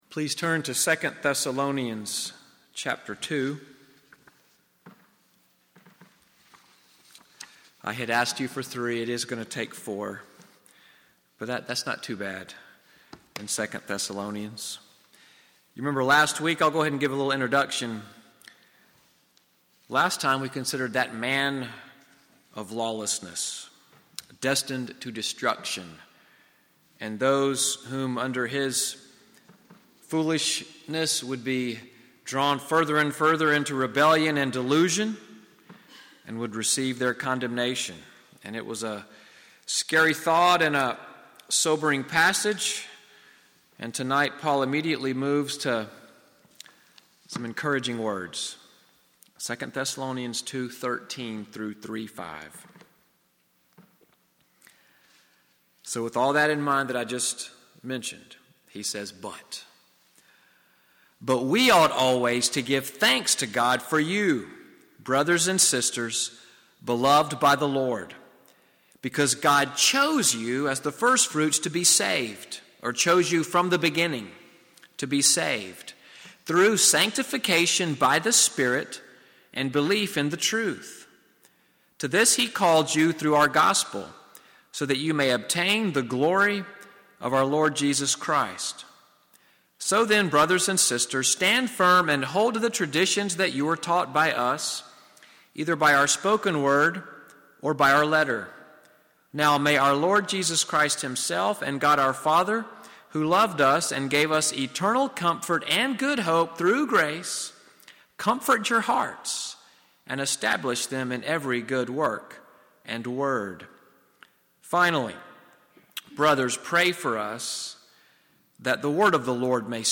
Evening Worship at NCPC-Selma, audio from the sermon, “Prayers and Benediction,” preached Sunday evening February 25, 2018.